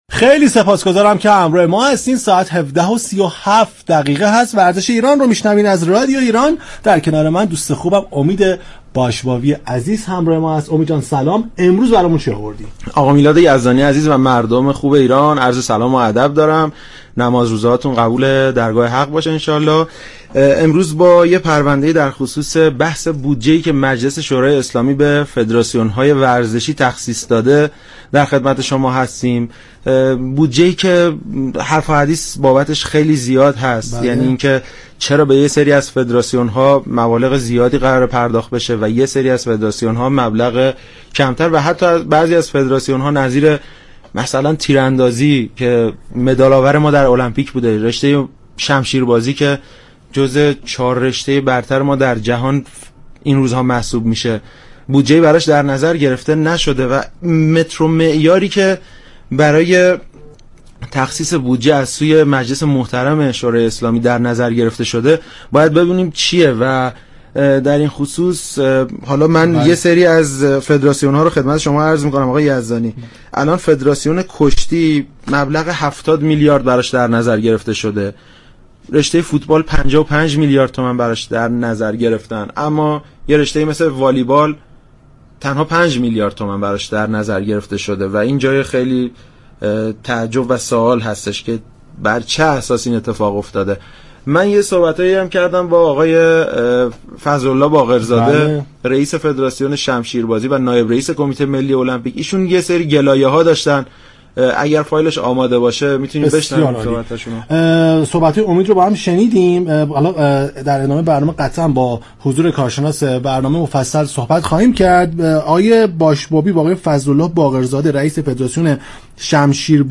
میرتاج الدینی بخش دیگر صحبت هایش را به بودجه والیبال اختصاص داد وگفت: بودجه والیبال كم در نظر گرفته شده است، با كمیسیون فرهنگی صحبت میكنم تا بخشی از این اعتبار در اختیار وزارت ورزش قرار گیرد نائب رئیس كمیسیون برنامه بودجه در بخش دیگر درباره بودجه تنیس روی میز ، شنا، بسكتبال نیز گفت: باید بر اساس اعتبارات وزارت ورزش و نیازهای آنها بودجه تخصیص داده شود. در بخش دیگر این گفت و گوی رادیویی، مهدی فروردین رئیس فراكسیون ورزش به روی خط آمد و با بیان اینكه این اعتبارات غیرقابل تغییر هستند، گفت: فدراسیون ها مستقل هستند، كمیسیون تلفیق برای كمك به وزارت ورزش این مبالغ را اختصاص داده است.